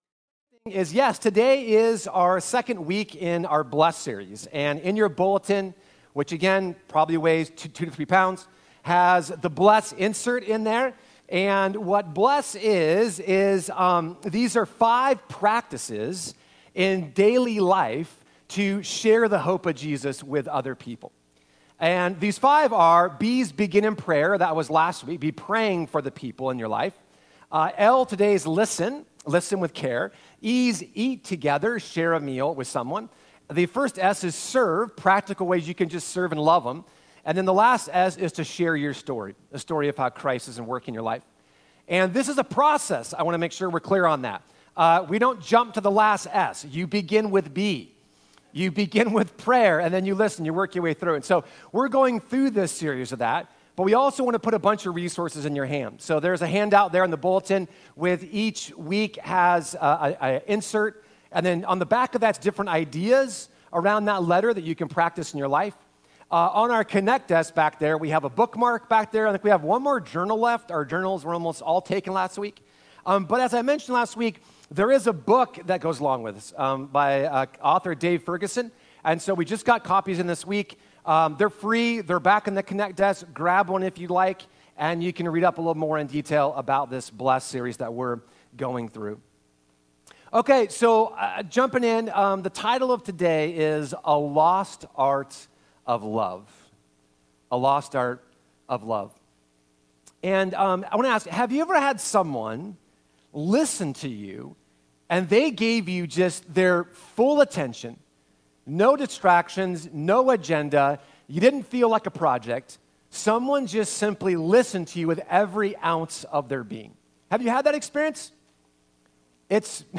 Sermons | Arbor Heights Community Church